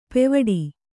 ♪ pevaḍi